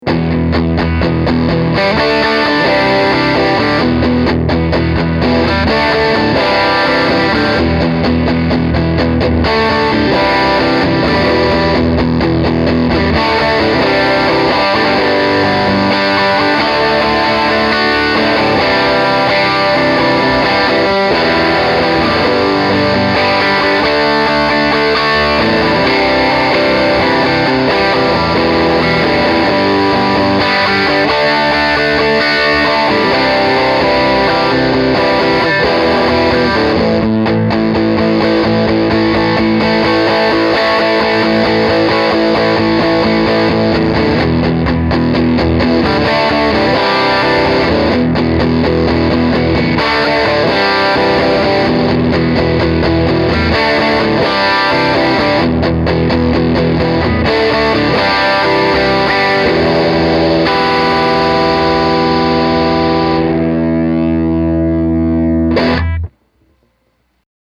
I put together some quick clips to demonstrate the sound of this guitar (all clips were played through my beloved Aracom PLX18 “plexi” clone):
First, we’ve got the riff to Neil Young’s “Rockin’ in the Free World”
Amp was cranked, and I played it through the bridge pickup.